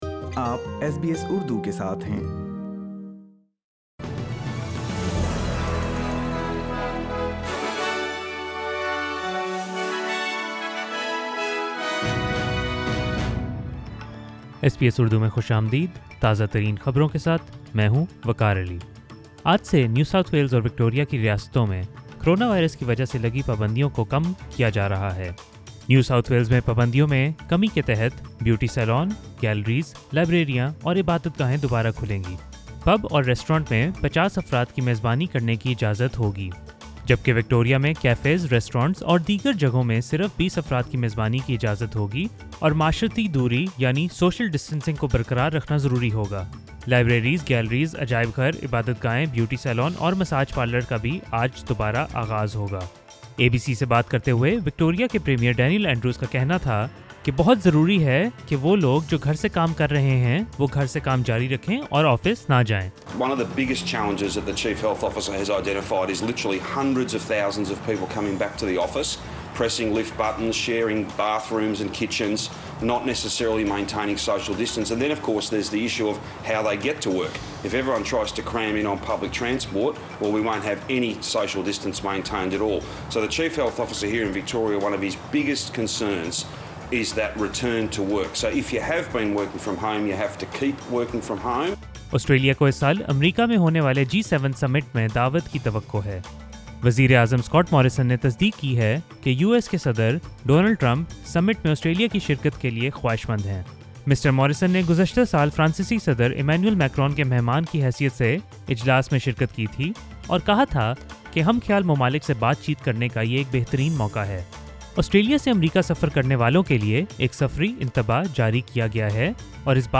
SBS Urdu News 1 June 2020